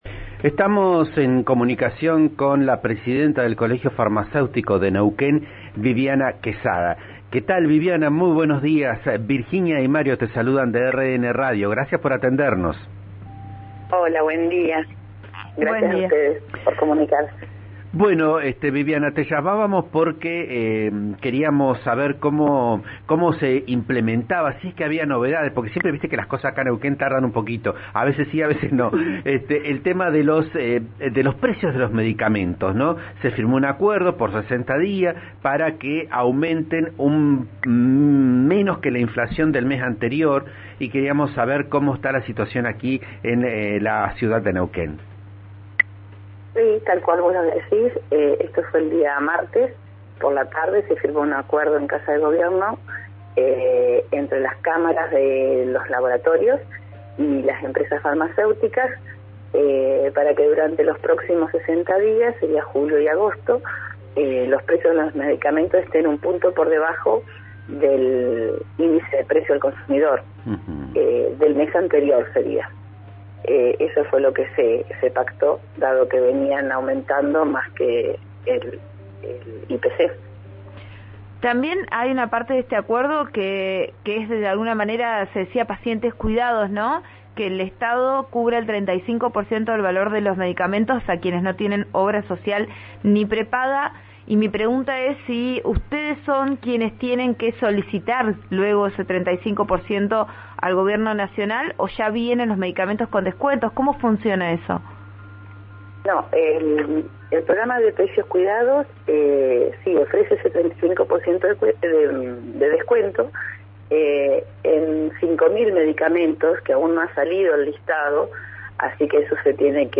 habló en Vos a Diario por RN Radio y comentó que algunos medicamentos no se consiguen en todo el país.